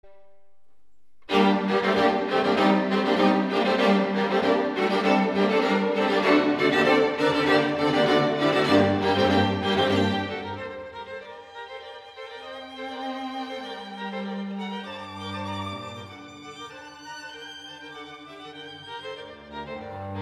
هذه المره تبدأ الآلات جميعها بعزف اللحن بقوة عاصفة وبصوت عالي جداً (فورتيسمو ff)، ثم تتبدل الأدوار فيعزف الكمان وحيدا اللحن متوسلا بضعفه، يتضح هنا التباين في هذه التنويعه بين القوة والاتحاد بالعزف وبين الضعف والانفراد، حتى تصل الى مرحلة من الشد والجذب بين الكمان والتشيلو